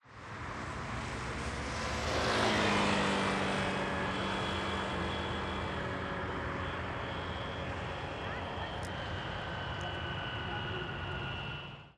CSC-18-098-GV - Moto Pequena Passando Rapido no Eixo Monumental.wav